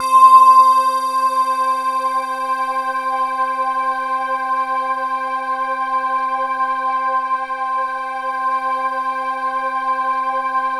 Index of /90_sSampleCDs/Infinite Sound - Ambient Atmospheres/Partition C/03-CHIME PAD
CHIMEPADC5-R.wav